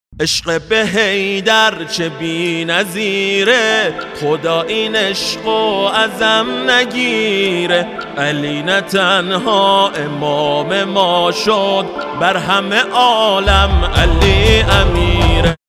زنگ موبایل
رینگتون موبایل غدیری و با نشاط
(باکلام فارسی)